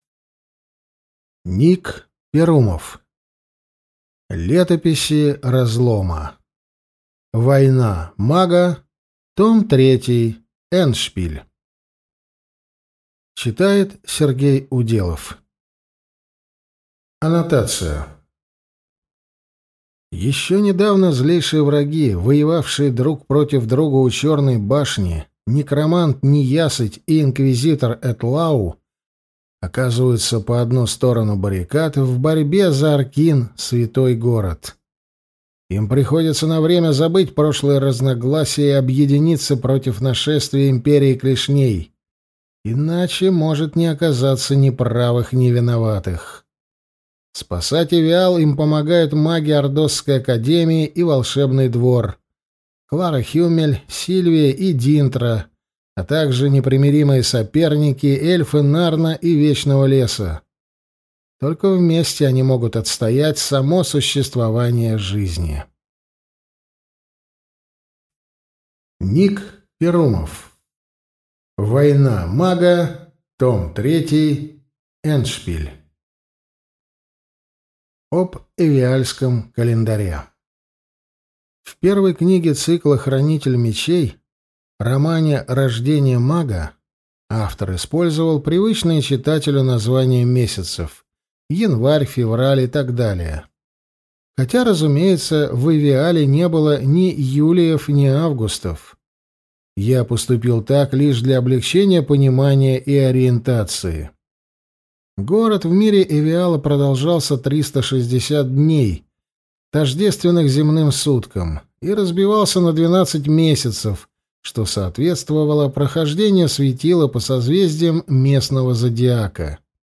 Аудиокнига Война мага. Том 3. Эндшпиль | Библиотека аудиокниг